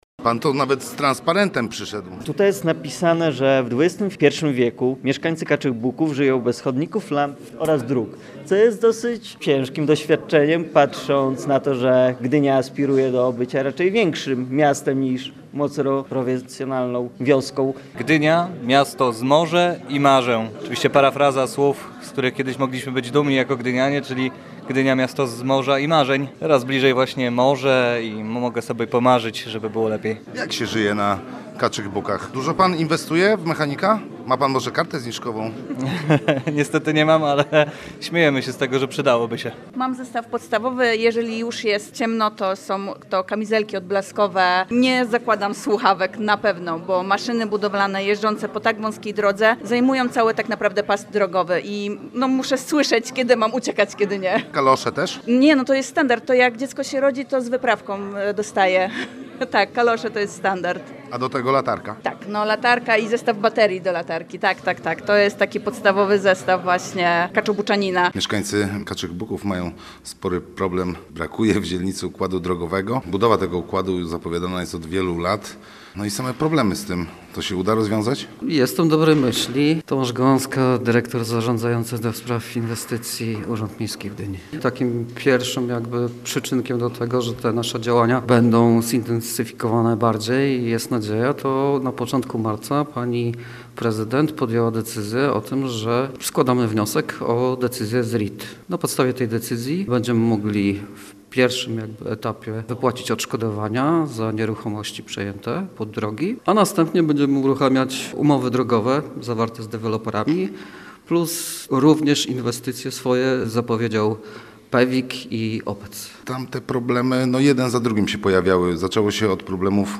Spotkanie mieszkańców Kaczych Buków z władzami Gdyni (fot.
Posłuchaj materiału naszego reportera: https